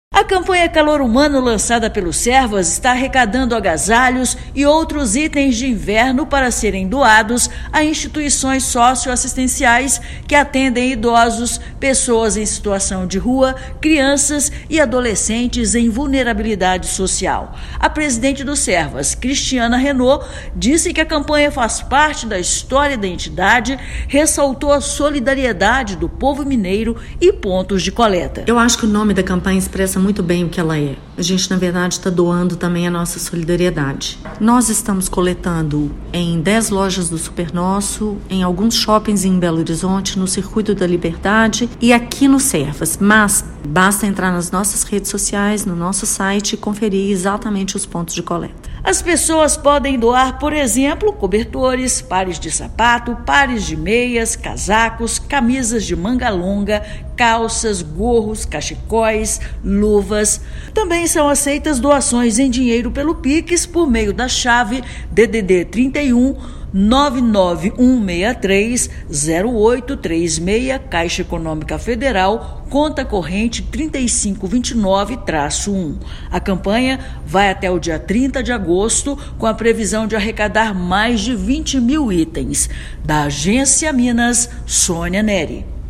Expectativa é de arrecadar 20 mil itens que serão doados para instituições que atendem pessoas em situação de vulnerabilidade. Ouça matéria de rádio.